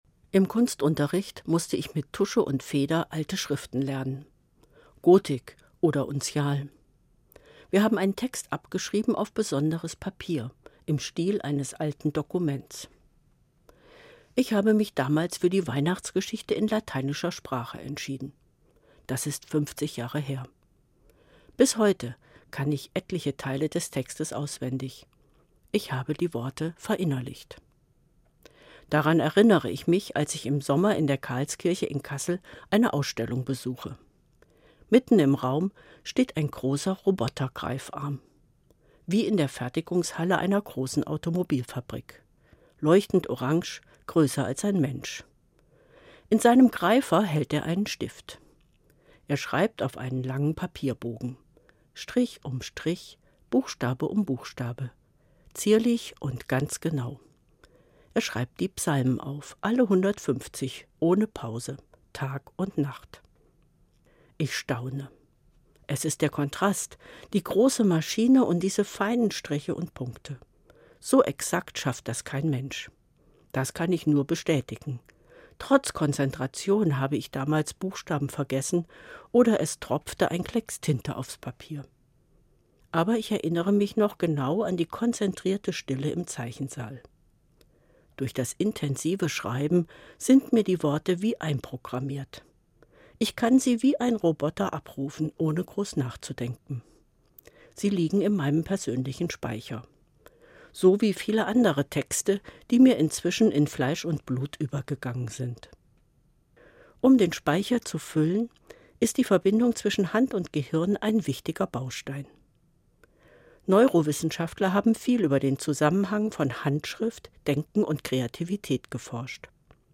Evangelische Pfarrerin, Kassel